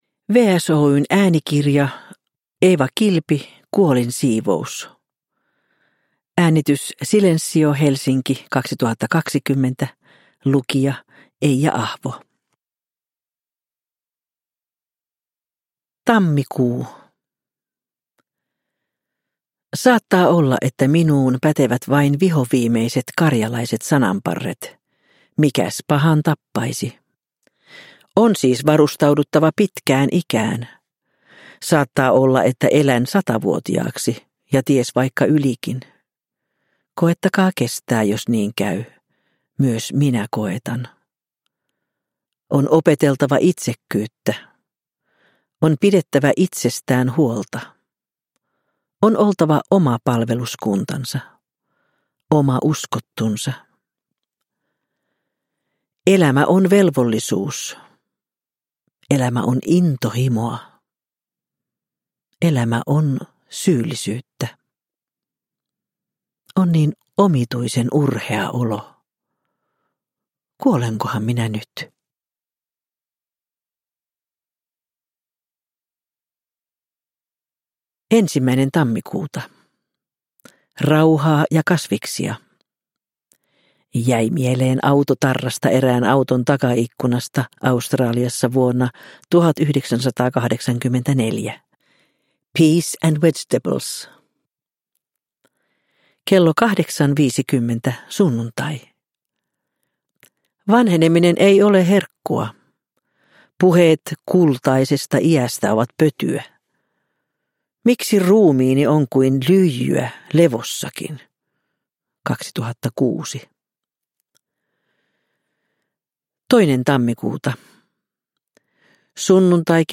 Kuolinsiivous – Ljudbok – Laddas ner
Uppläsare: Eija Ahvo